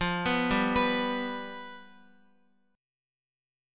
Fsus2b5 Chord